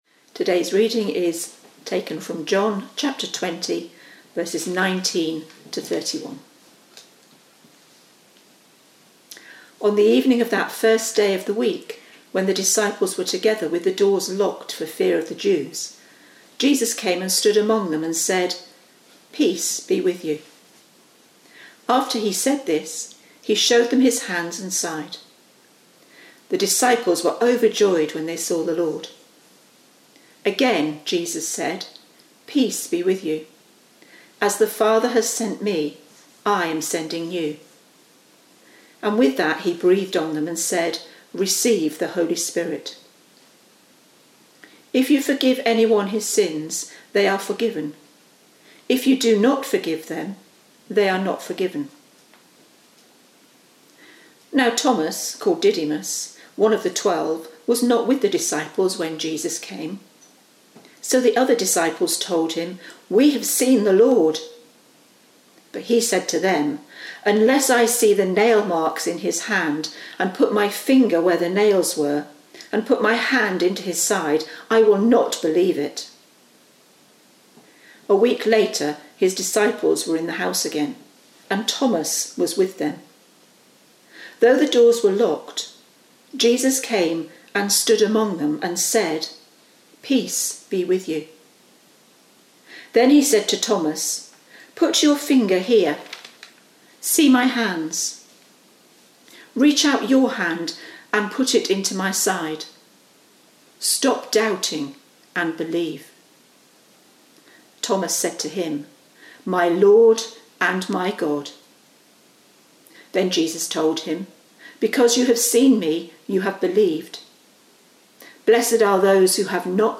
Sunday-26th-April-Service.mp3